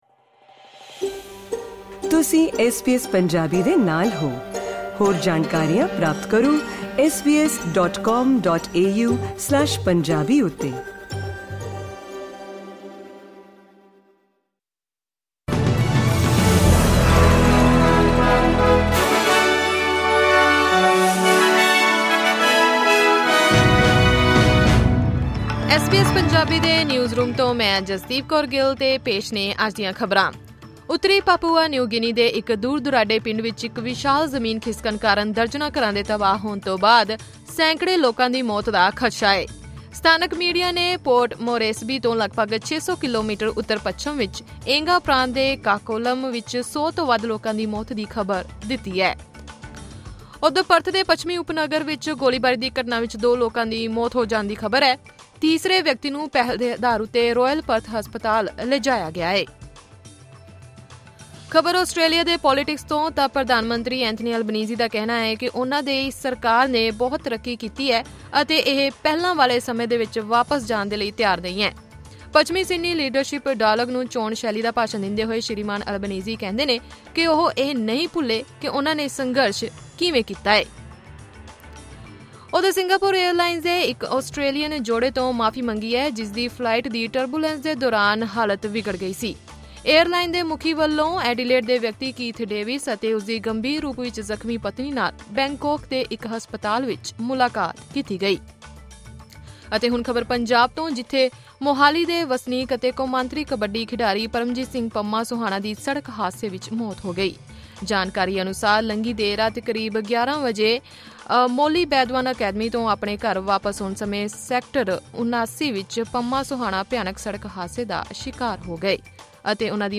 ਐਸ ਬੀ ਐਸ ਪੰਜਾਬੀ ਤੋਂ ਆਸਟ੍ਰੇਲੀਆ ਦੀਆਂ ਮੁੱਖ ਖ਼ਬਰਾਂ: 31 ਮਈ, 2024